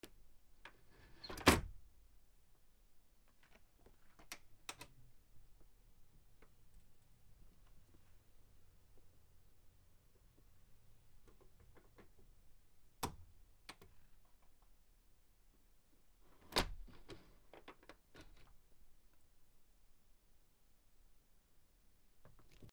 / K｜フォーリー(開閉) / K05 ｜ドア(扉)
ピアノ室のドアの開閉（ゆっくり）